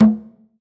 LCONGA LOW.wav